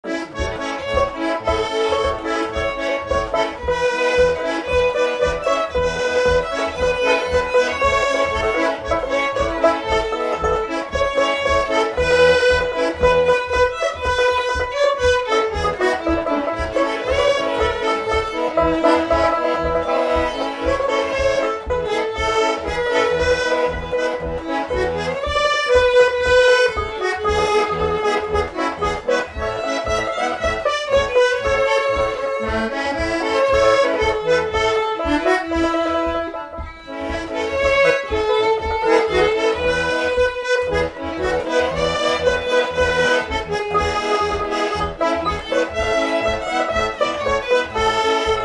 Instrumental
danse : fox-trot
Pièce musicale inédite